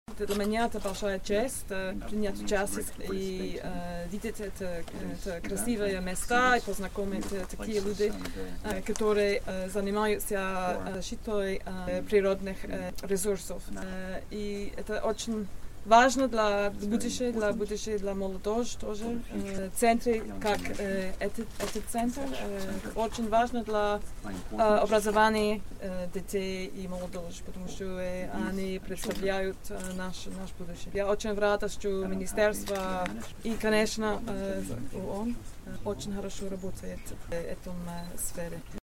О значимости проекта рассказала посол Великобритании Фиона Гибб.